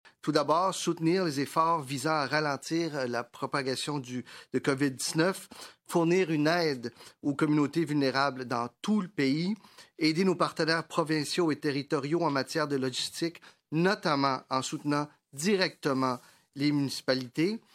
En point de presse ce midi, le ministre de la Défense Harjit Sajjan a expliqué les trois priorités d’intervention, que son collègue Pablo Rodriguez a ensuite relayé en français.